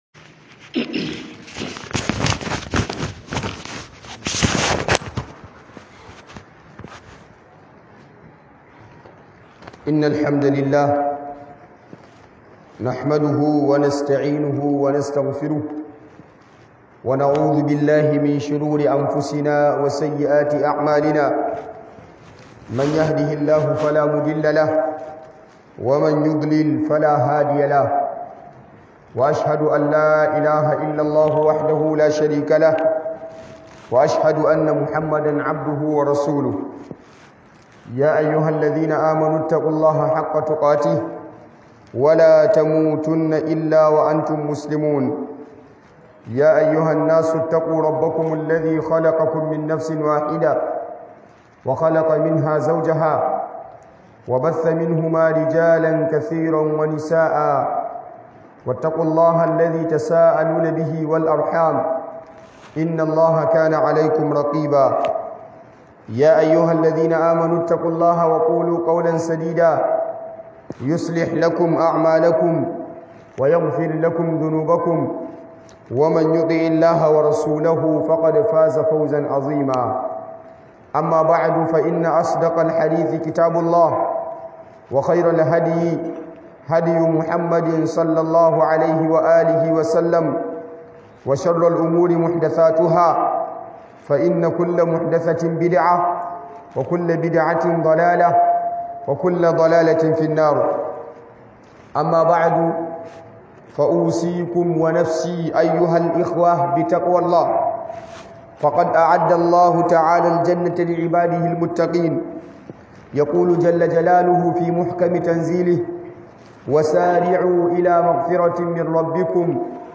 Book HUDUBOBI